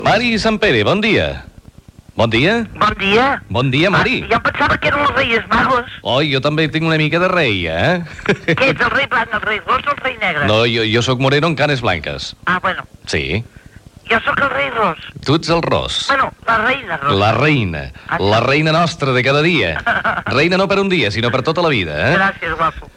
Participació de l'actiu Mary Santpere.